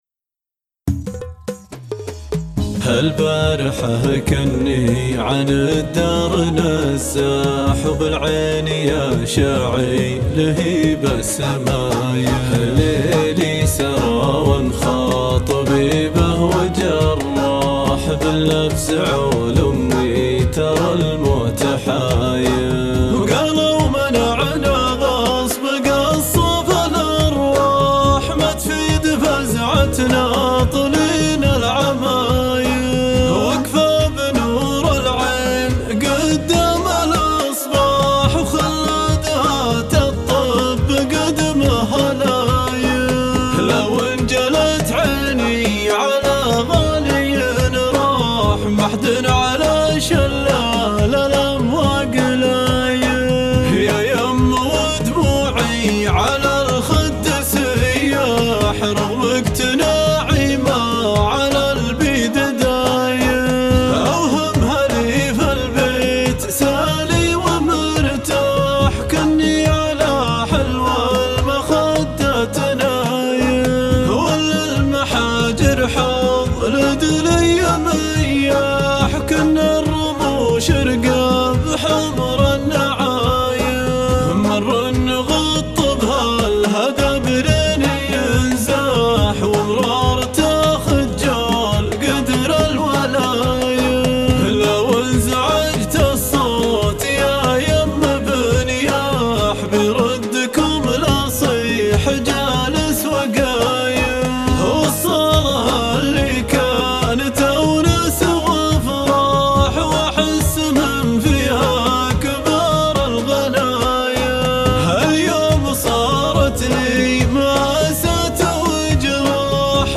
ايقاع